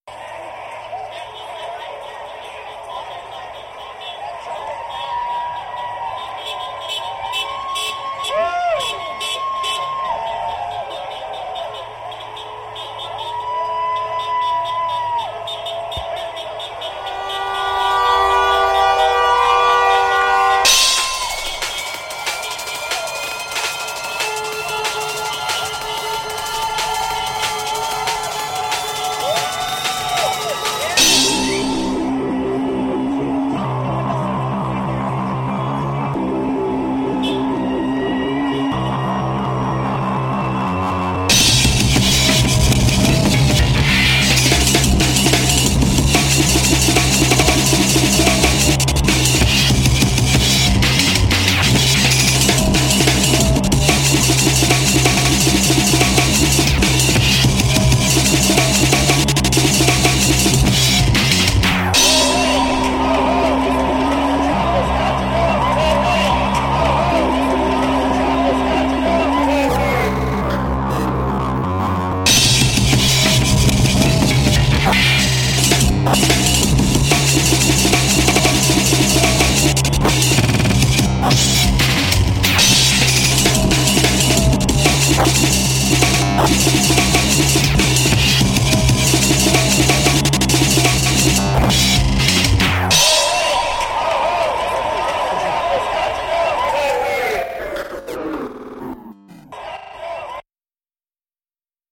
LA women's march reimagined